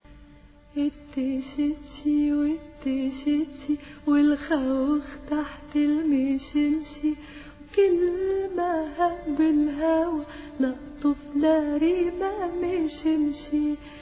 sweet lullaby